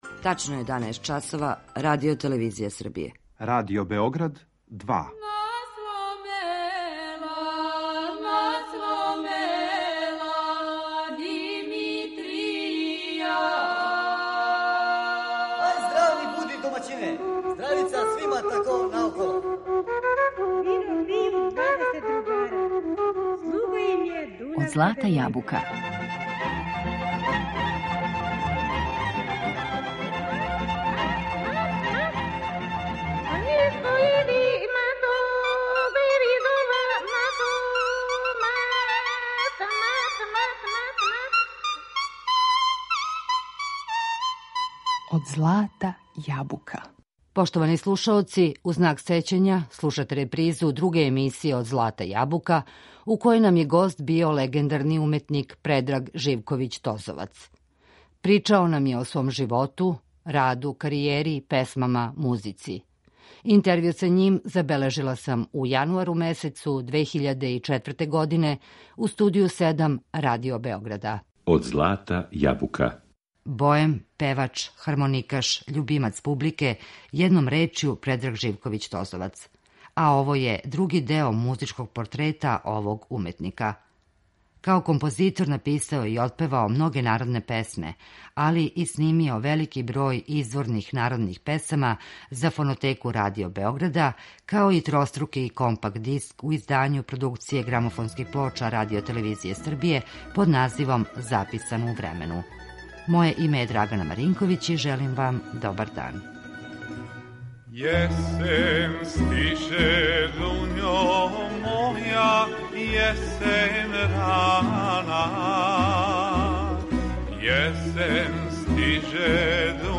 Разговор је забележен у јануару 2004. године у Студију 7 Радио Београда.